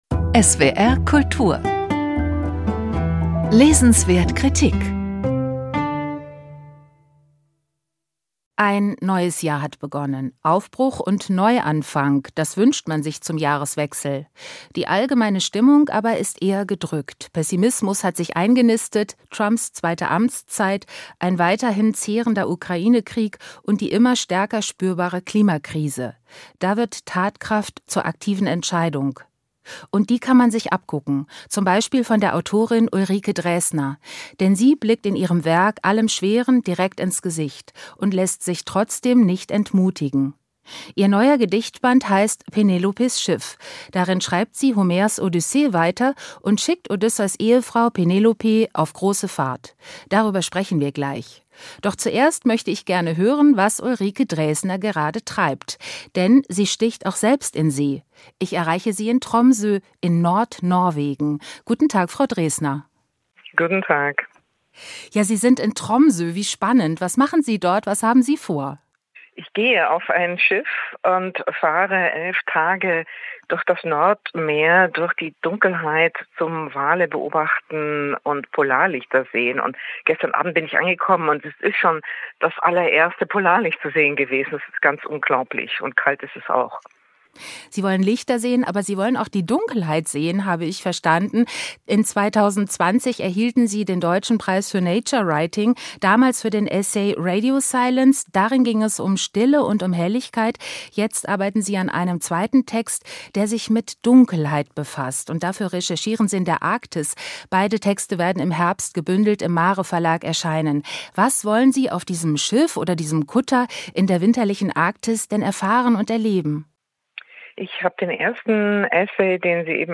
im Gespräch mit Ulrike Draesner